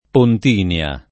Pontinia [ pont & n L a ]